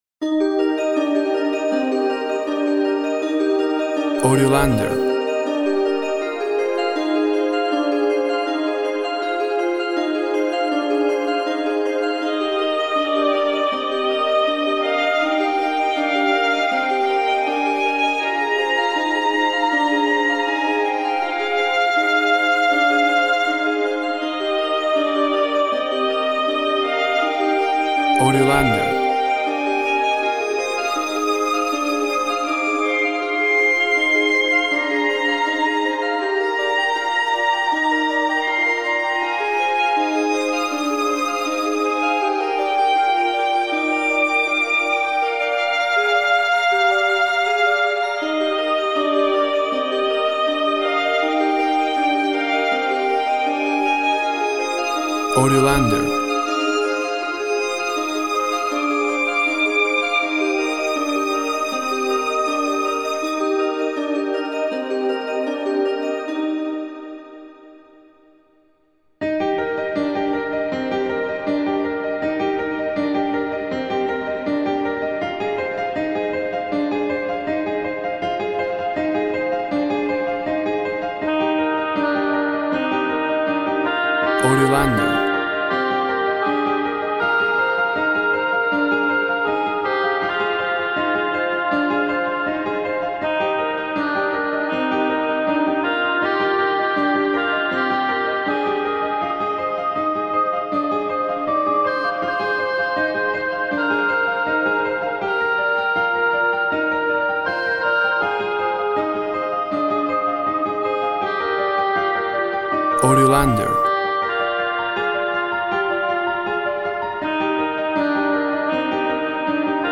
WAV Sample Rate 24-Bit Stereo, 44.1 kHz
Tempo (BPM) 78